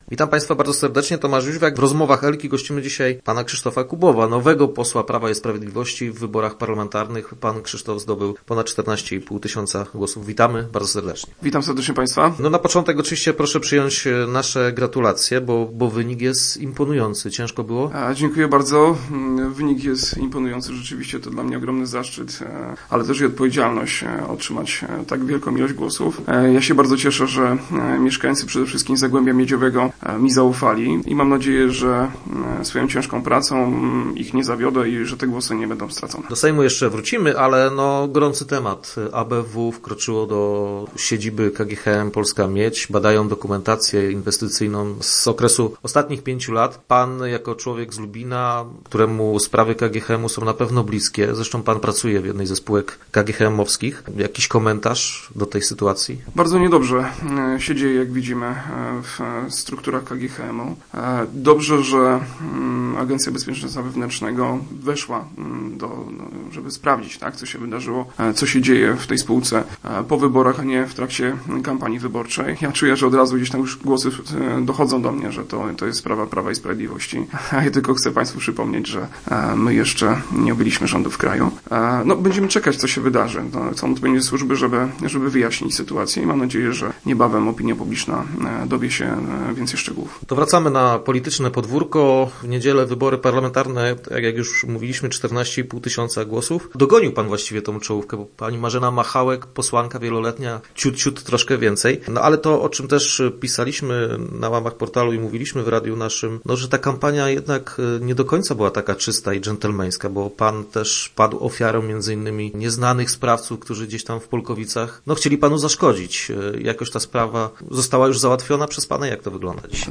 Jak w wieku 31 lat wchodzi się do wielkiej polityki? O tym opowiadał nasz gość, Krzysztof Kubów, lubiński poseł Prawa i Sprawiedliwości.